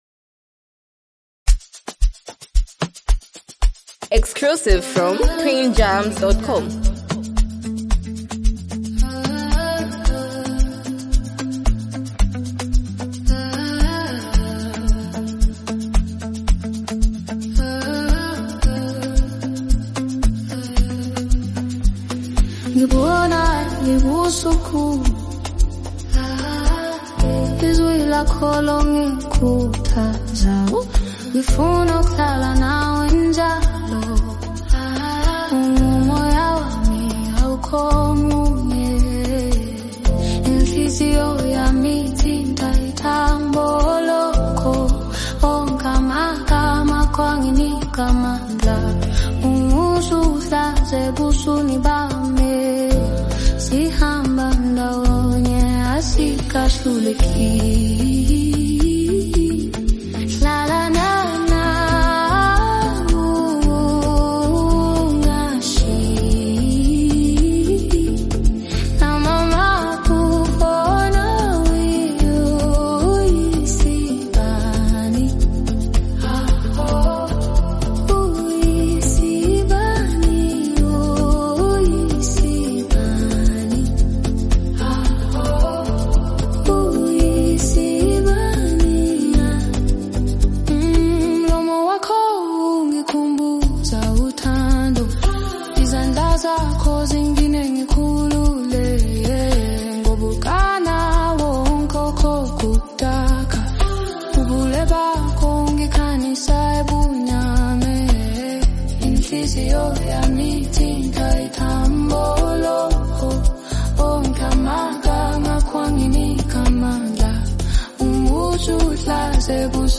a deeply emotional amapiano track that revolves around love
with her rich and grounded vocal style
a touching and soulful amapiano record